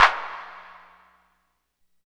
88 FT CLAP-R.wav